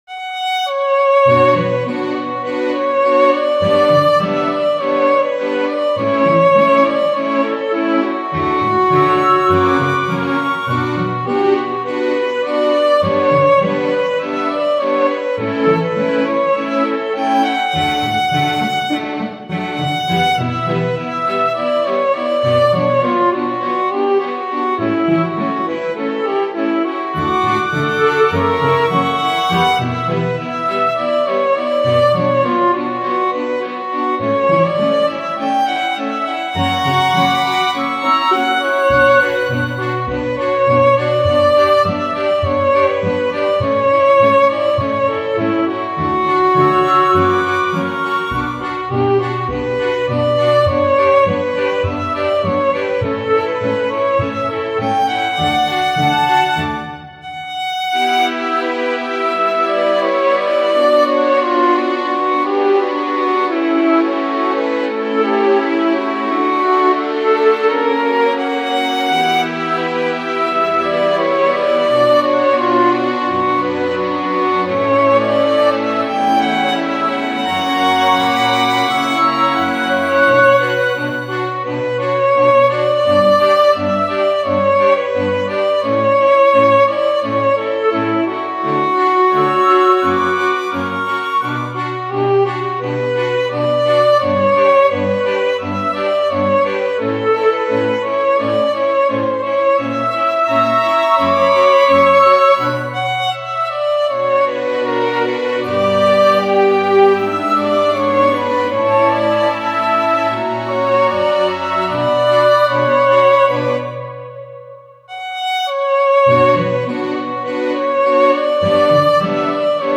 ogg(L) しっとり バイオリン 切ない
バイオリンが切なく歌う。